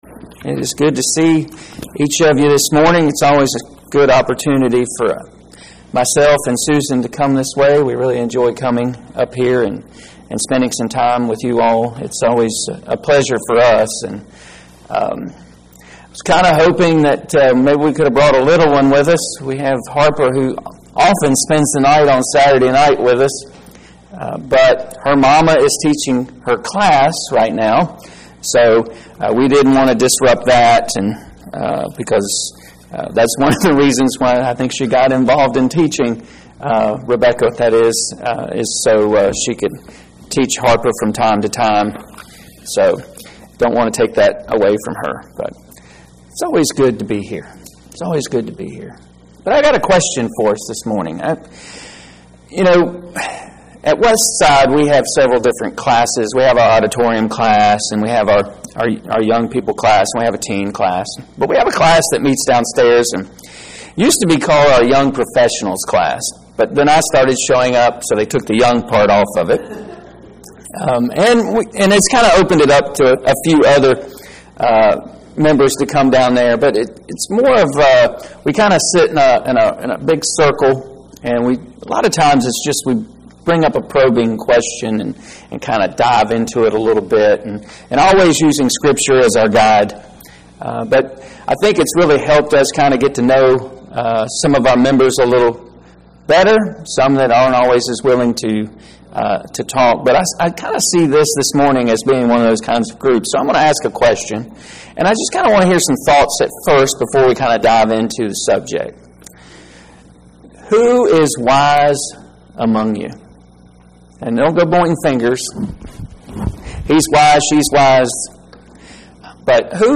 5-18-25 Bible Study – Who is Wise Among You?